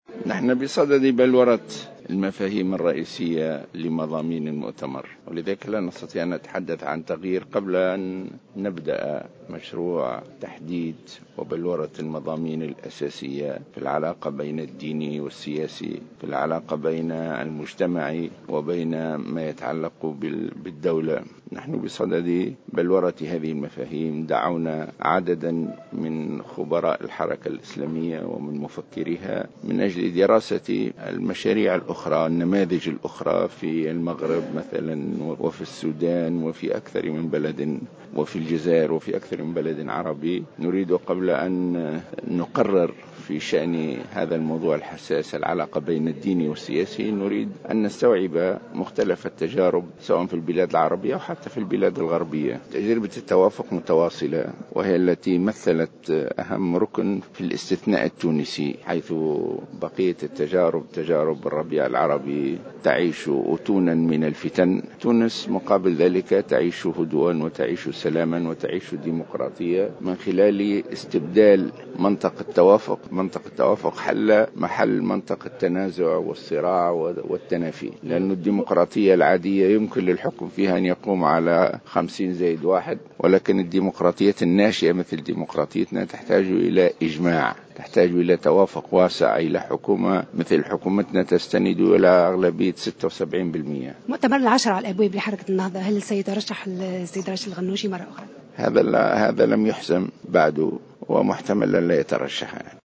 Le président du mouvement Ennahdha Rached Ghannouchi a indiqué ce samedi 28 février 2015 dans une déclaration accordée à Jawhara FM, qu’il n’a toujours pas décidé de se présenter ou non, à nouveau à la présidence du mouvement.